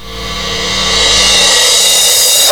REVERSCYM2-R.wav